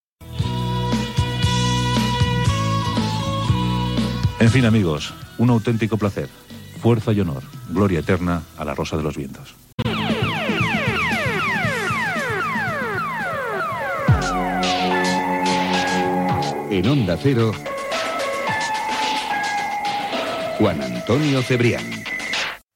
Comiat del programa i careta de sortida
Entreteniment